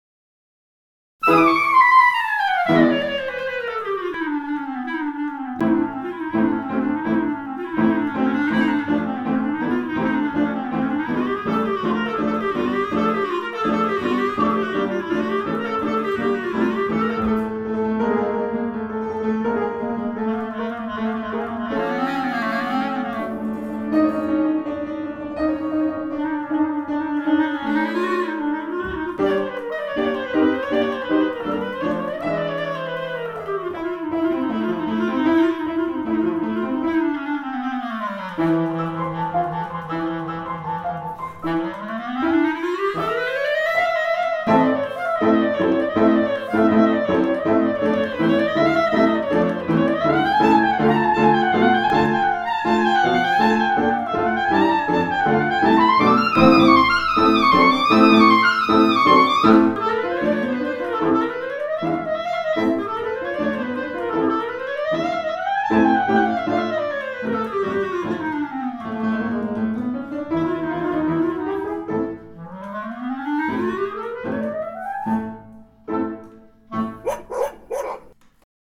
Chromatická technika video
Interprét vynechal prvú voltu a išiel rovno na druhú voltu.